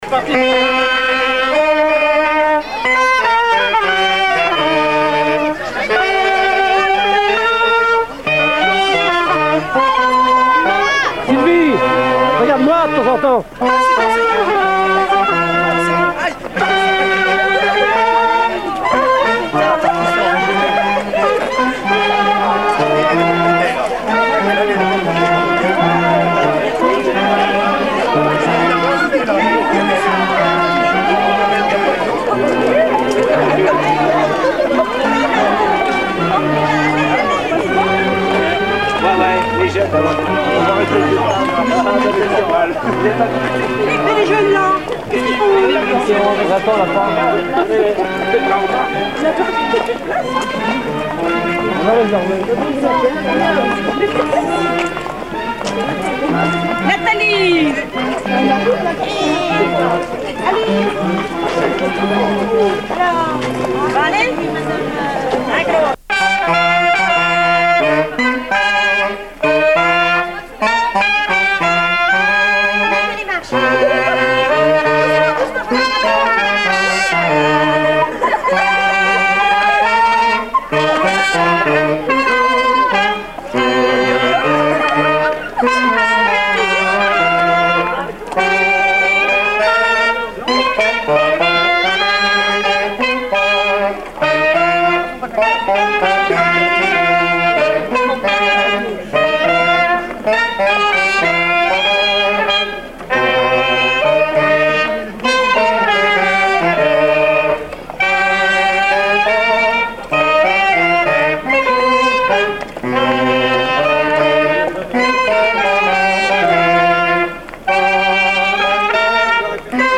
circonstance : fiançaille, noce
Marches de cortège de noce
Pièce musicale inédite